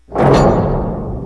DOORS